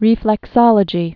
(rēflĕk-sŏlə-jē)